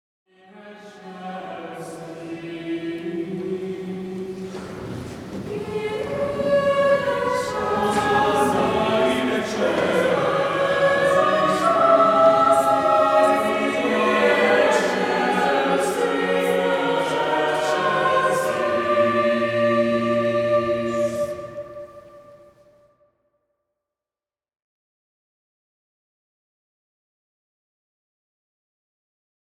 —Live recording by a volunteer choir.